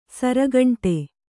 ♪ sara gaṇṭe